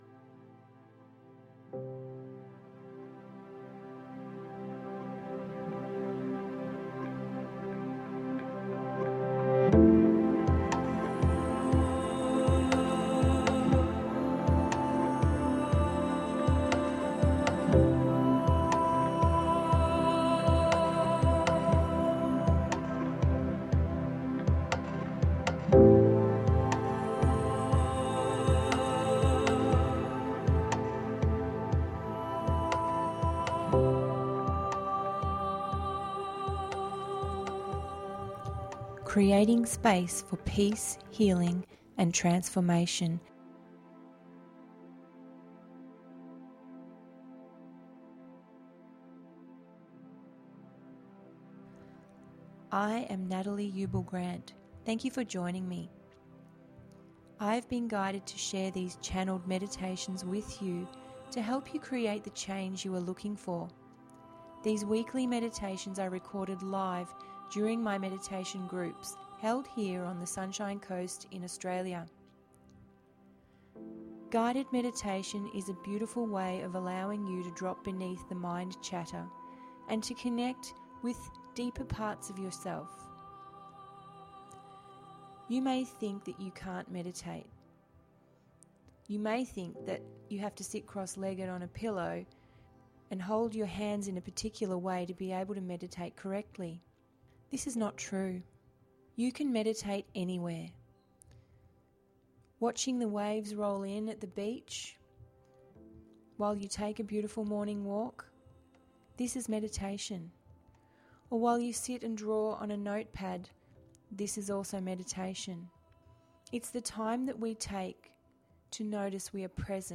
Today's guided meditation starts by writing a list of feelings around a moment in time that was important to you.